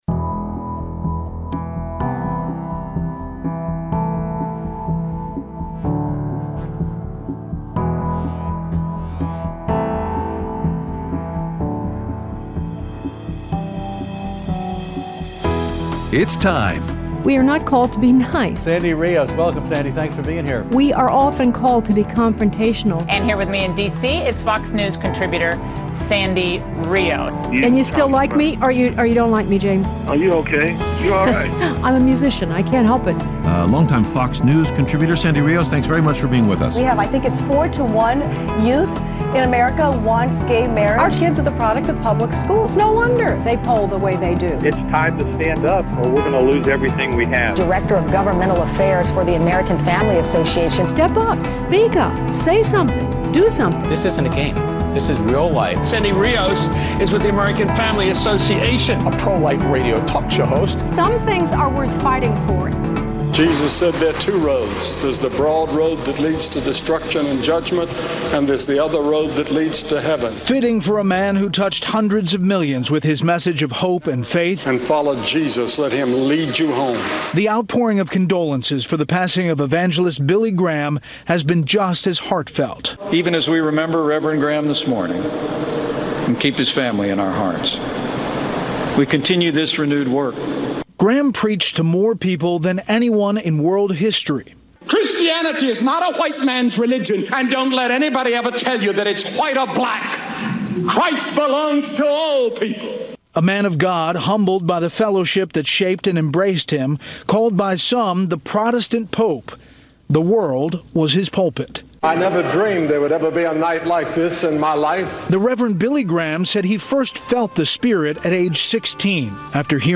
Encore of: CPAC 2018 with CPAC Chairman Matt Schlapp, Chinese Expert Gordon Chang, and Former Federal Prosecutor Sidney Powell